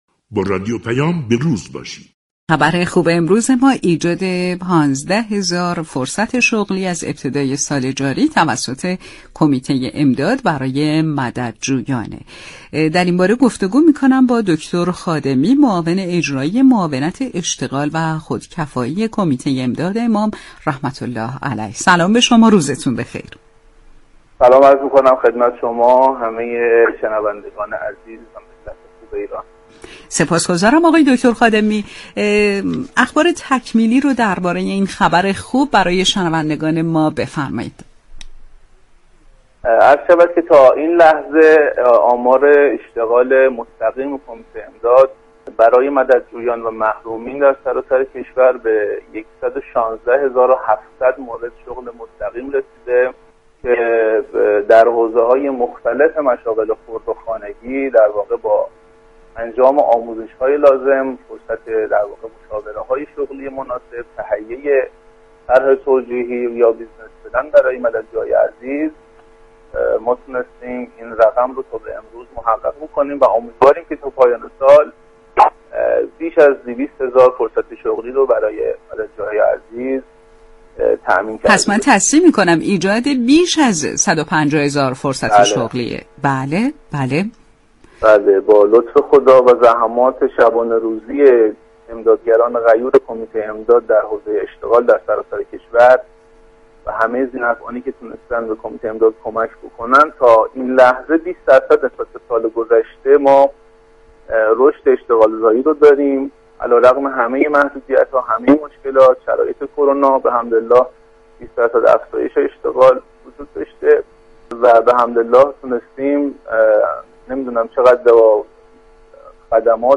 در گفتگو با رادیو پیام ، از ایجاد بیش از ١5٠ هزار فرصت شغلی تا پایان سال جاری توسط كمیته امداد امام خبر داد .